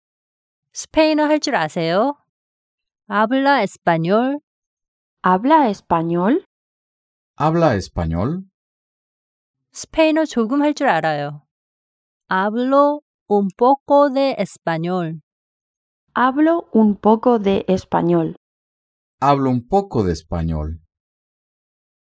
ㅣ아블라 에스빠뇰ㅣ
ㅣ아블로 운 뽀꼬 데 에스파뇰ㅣ